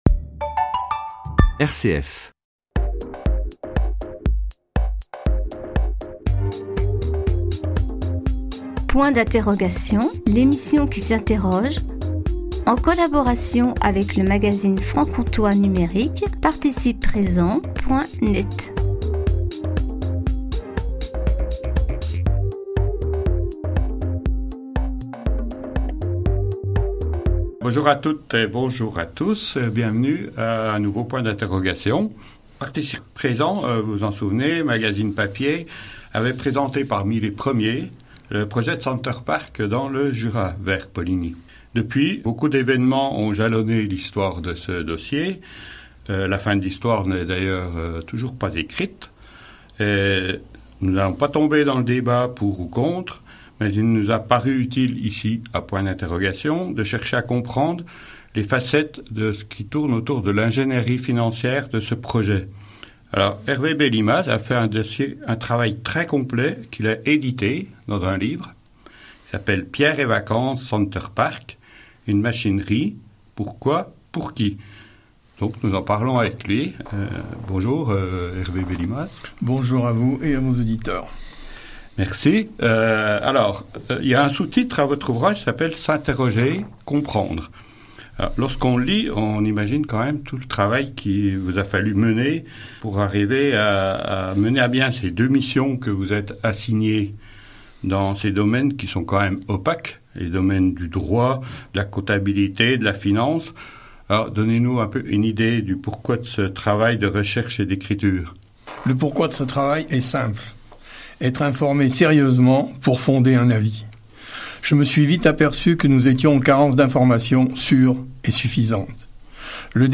RCF Interview